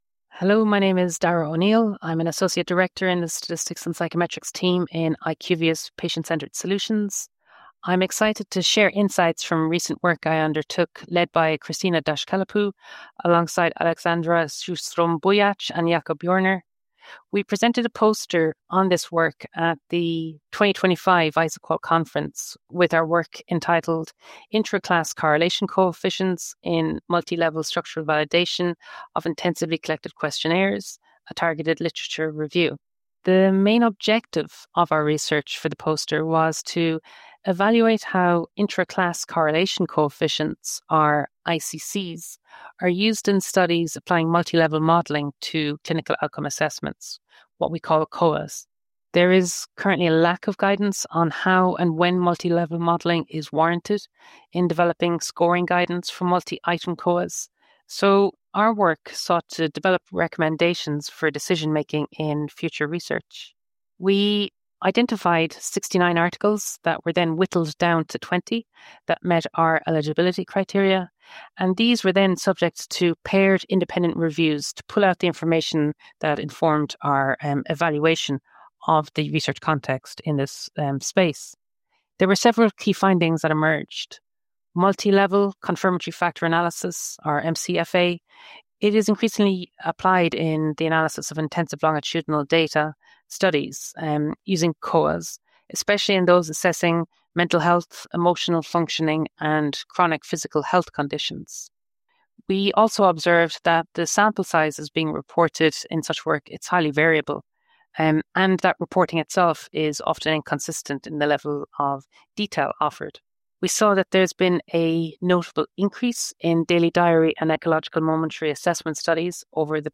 she provides an overview of the poster and key takeaways.